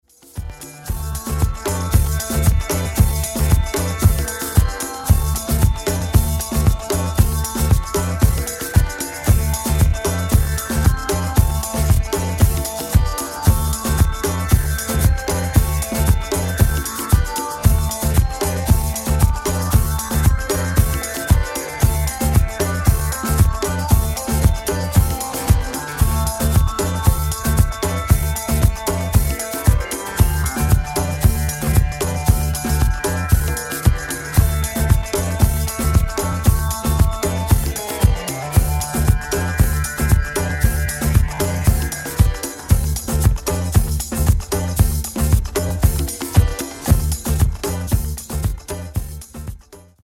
Genere:    Disco | Electro Funky